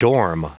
Prononciation du mot dorm en anglais (fichier audio)
Prononciation du mot : dorm